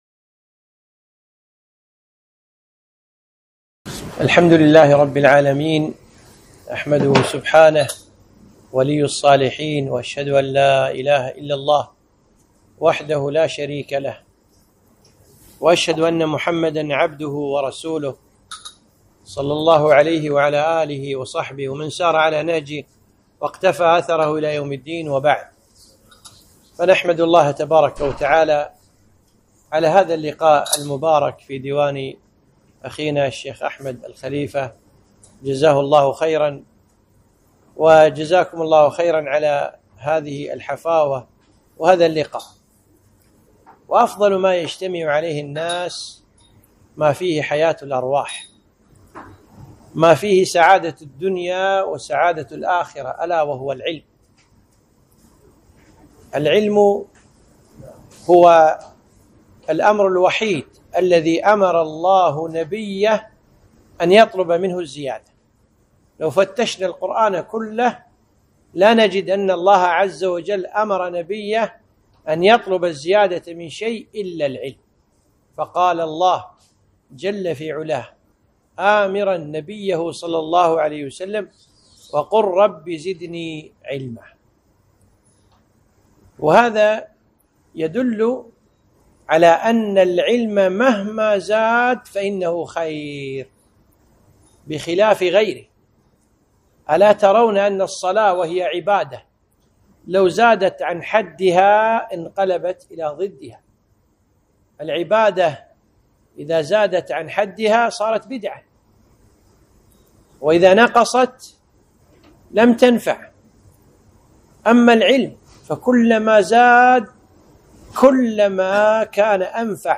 محاضرة - ( وقل ربي زدني علما)